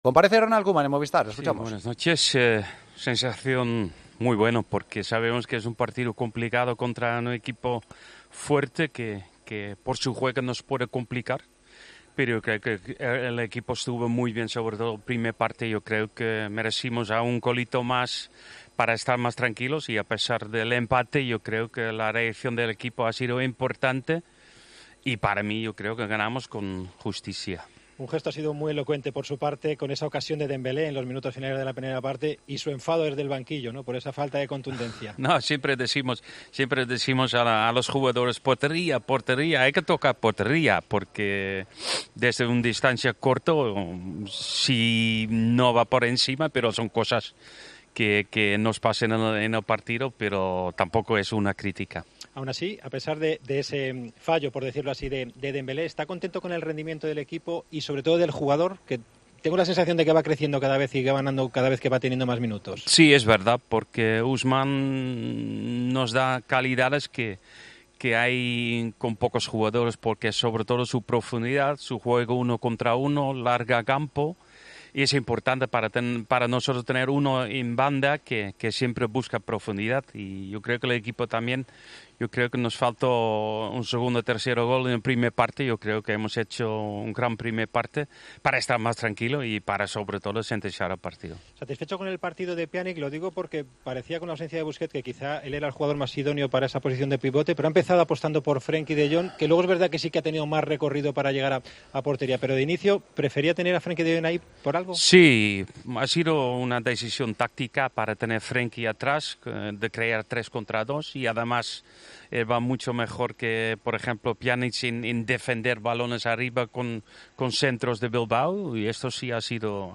El entrenador del Barcelona hablo en Movistar de la polémica filtración del contrato de Leo Messi publicada por El Mundo.